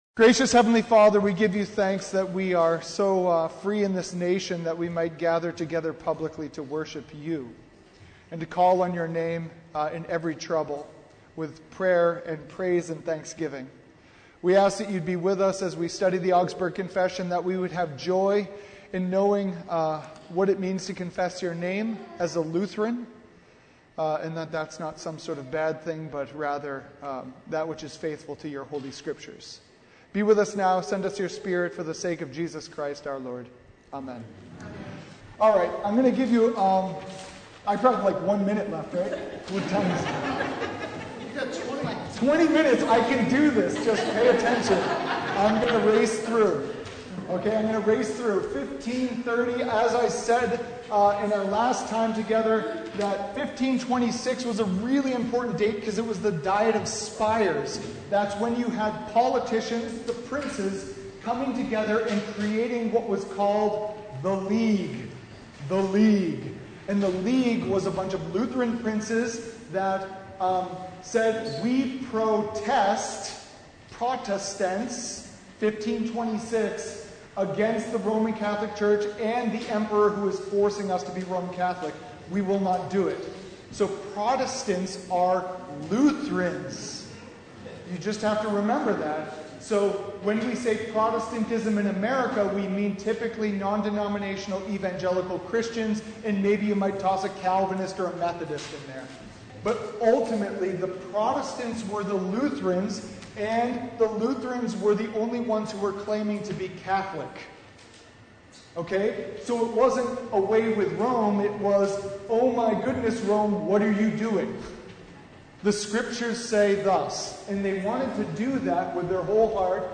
Service Type: Bible Hour
Topics: Bible Study